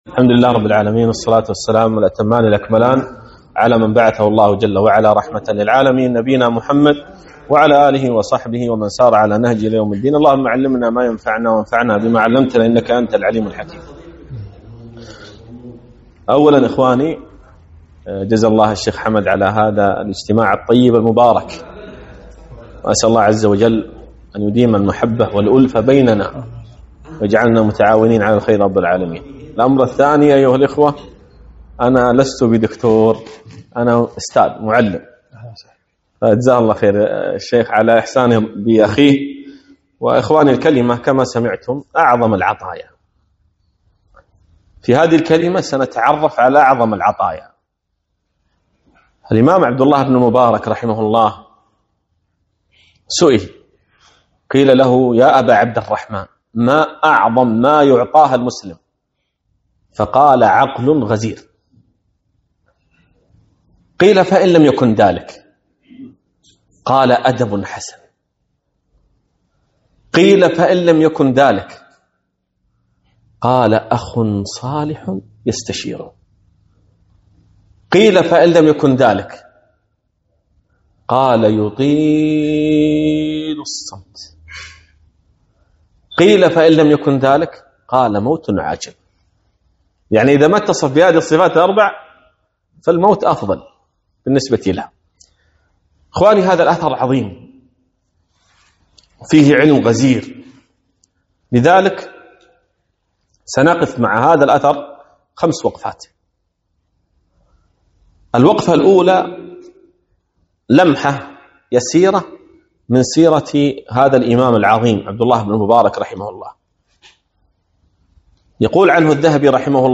محاضرة - أعظم العطايا